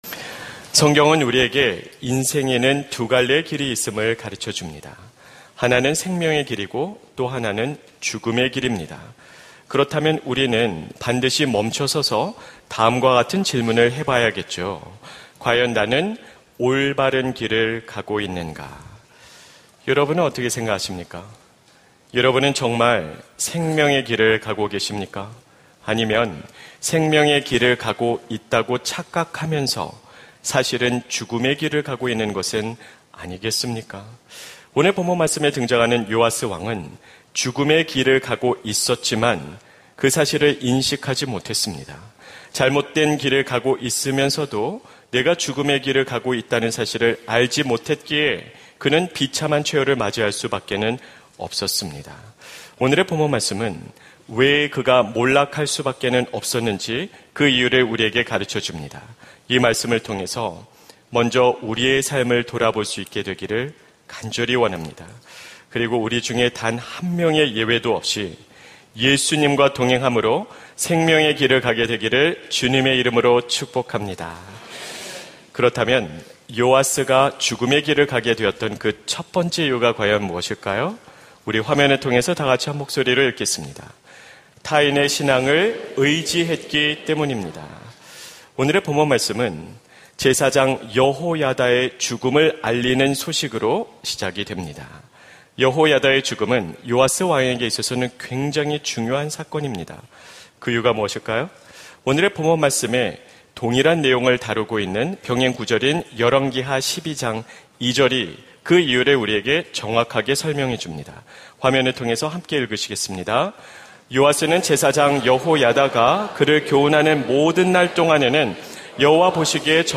설교 : 주일예배